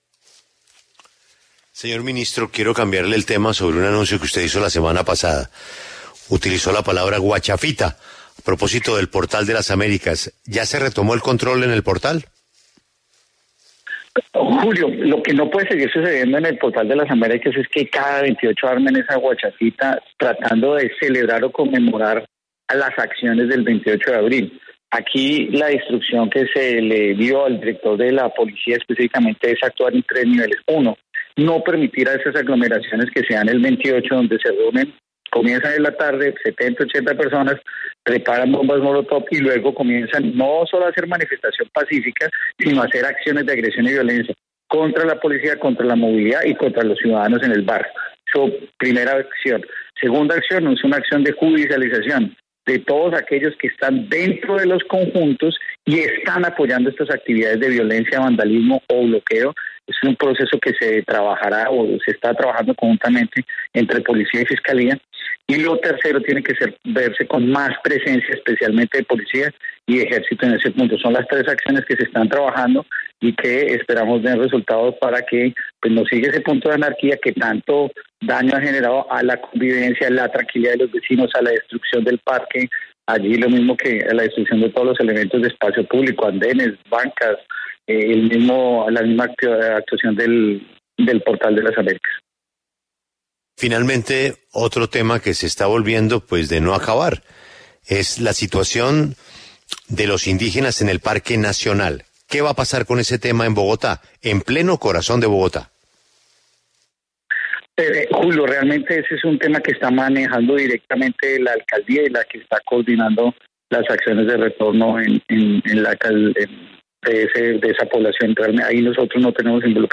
En diálogo con La W, el ministro de Defensa, Diego Molano, explicó cuáles serán los tres puntos clave de la fuerza pública para retomar el control de esta zona de Bogotá.